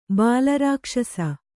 ♪ bāla rākṣasa